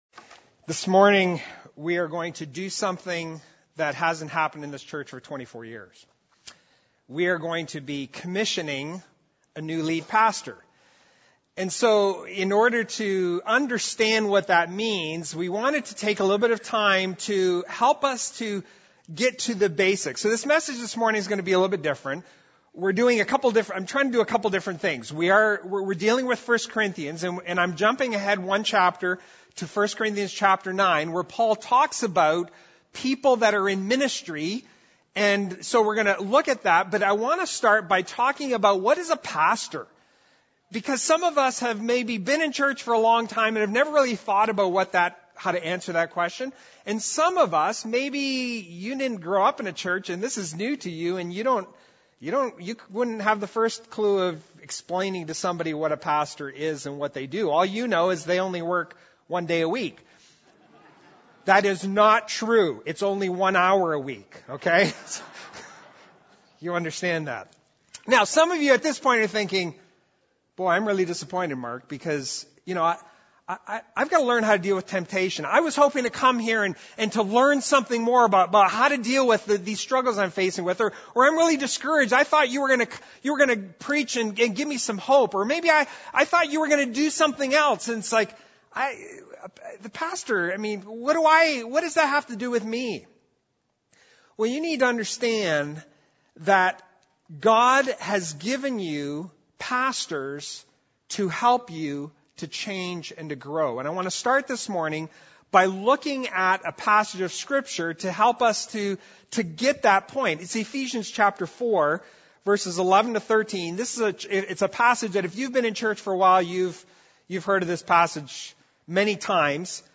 The sermon was presented to help the congregation have a better understanding of the role of a pastor.